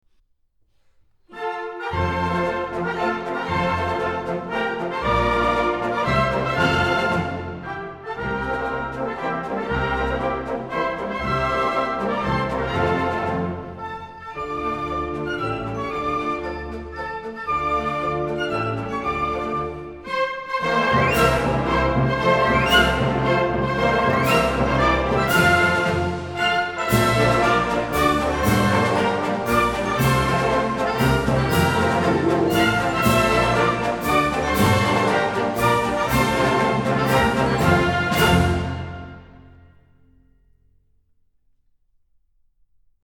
Гимн (mp3)
hymne_gre.mp3